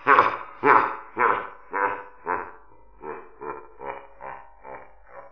laugh2.wav